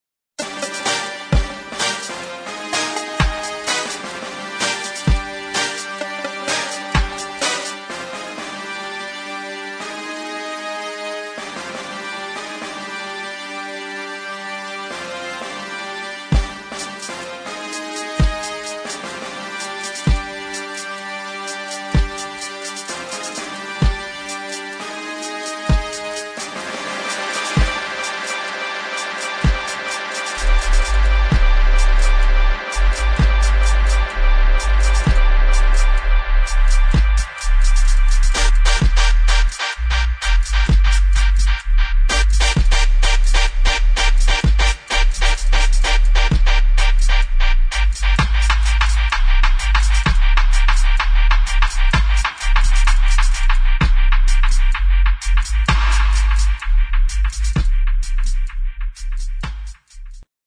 [ DUB | REGGAE ]